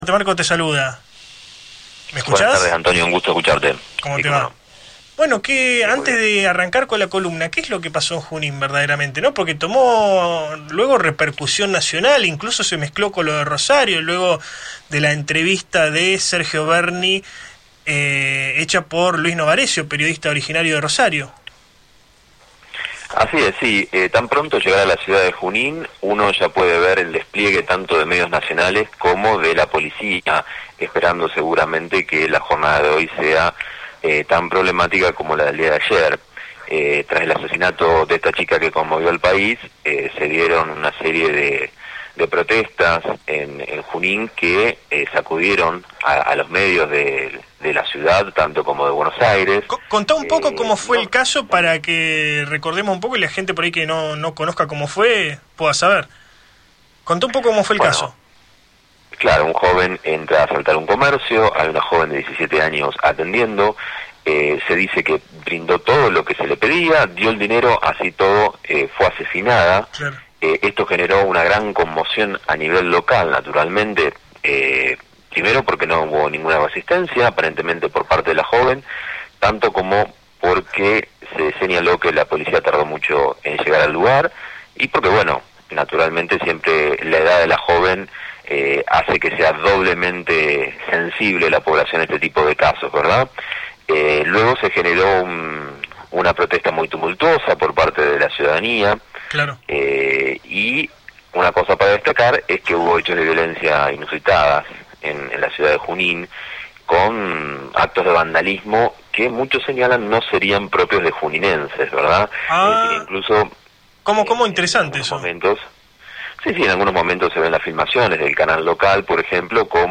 Radio Cadena de Noticias entrevistó